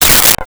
Space Gun 03
Space Gun 03.wav